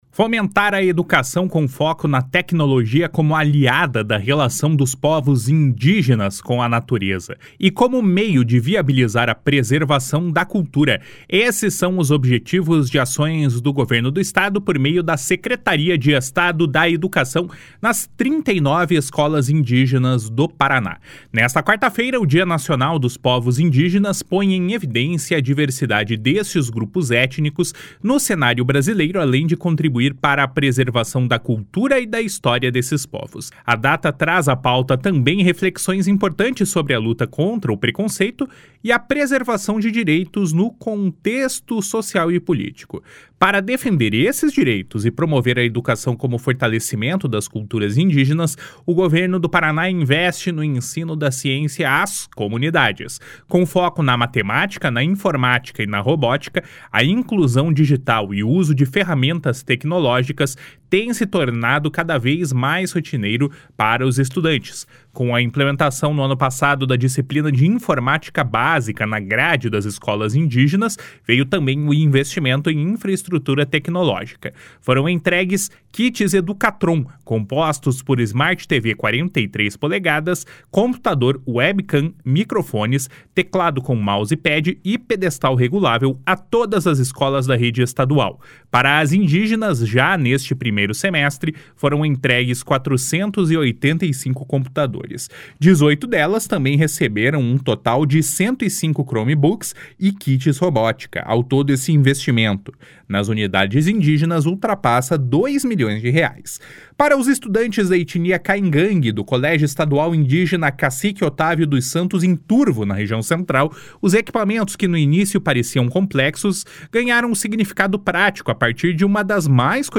O secretário de Estado da Educação, Roni Miranda, afirma que o objetivo é dar a todos os estudantes a mesma condição de aprendizagem. // SONORA RONI MIRANDA //